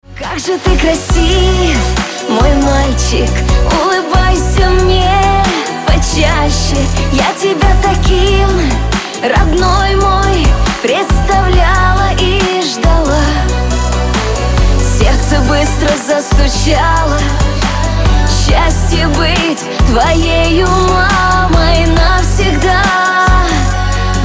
Спокойные рингтоны
Нежные рингтоны
Рингтоны шансон
Женский голос